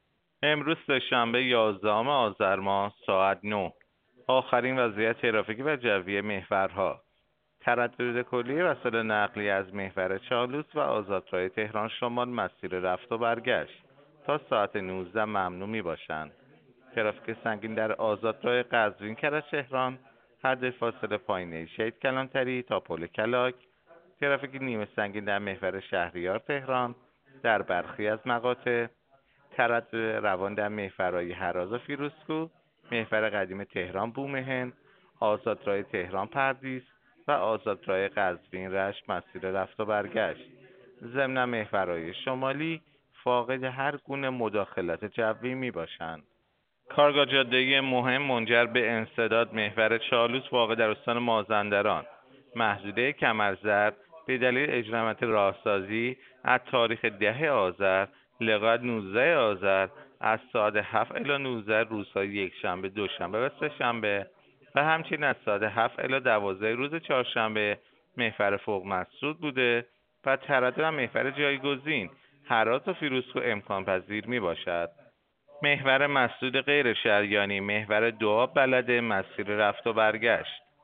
گزارش رادیو اینترنتی از آخرین وضعیت ترافیکی جاده‌ها ساعت ۹ یازدهم آذر؛